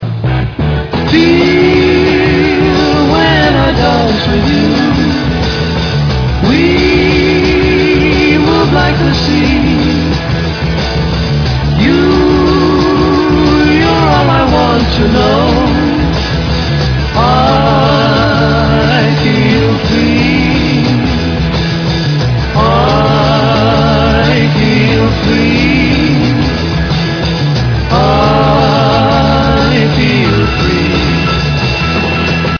Bass, harmonica, piano, cello,  vocals
Drums, vocals
Guitars, vocals